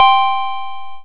今は無き某米国大陸横断クイズ番組の音をヒントにしました。QuizSound 1は解答ボタンを押したときの音、2は正解時の音、3は誤答時の音です。